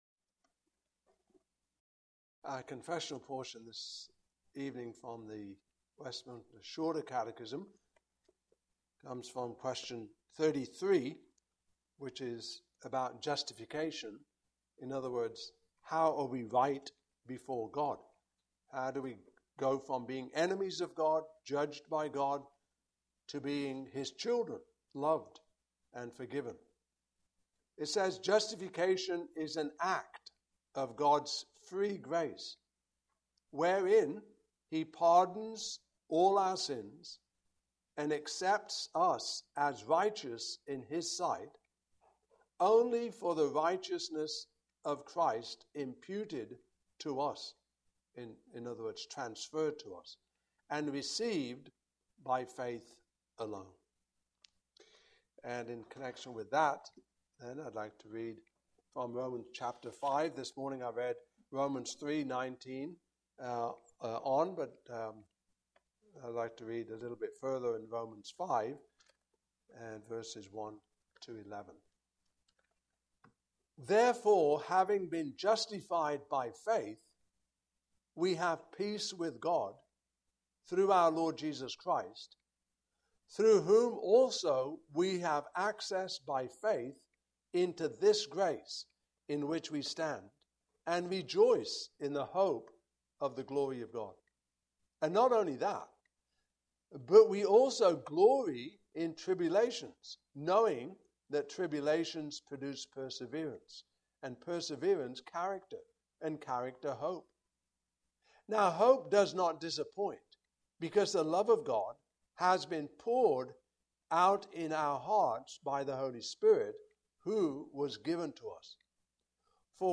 Westminster Shorter Catechism Passage: Romans 5:1-11 Service Type: Evening Service Topics